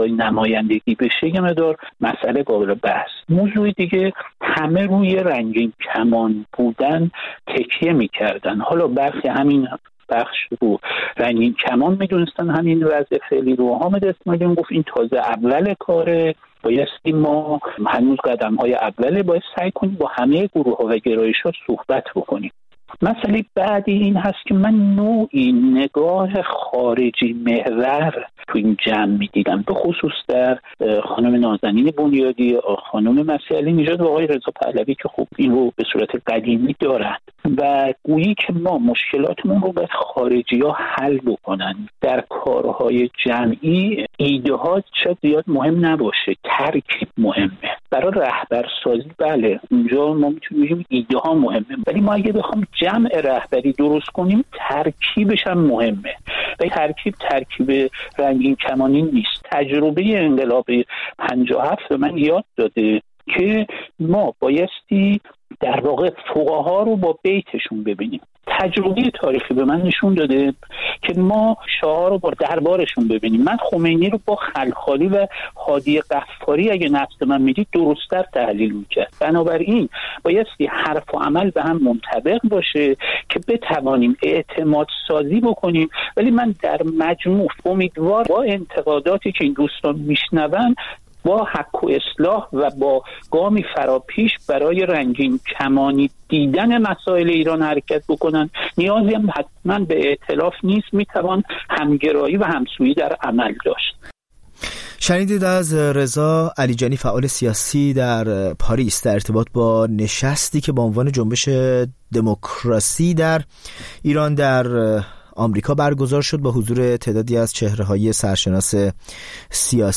در میزگردی